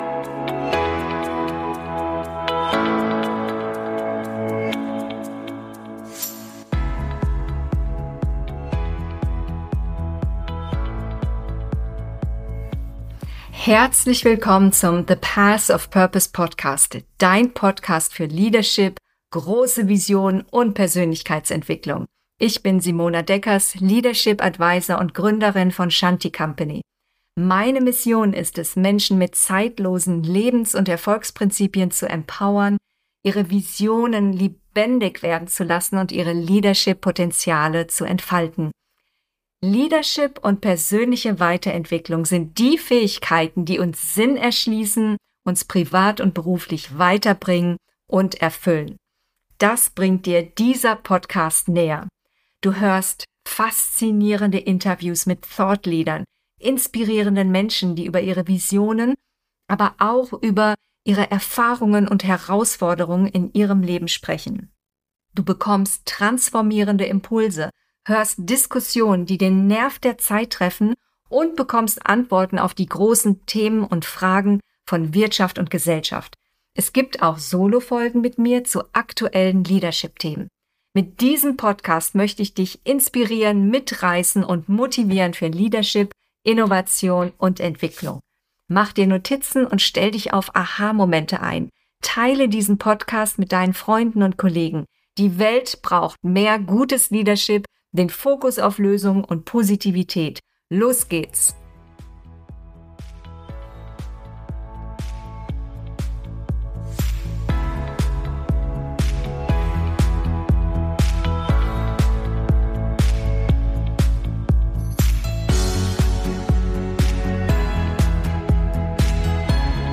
Innovation ist immer möglich! - Interview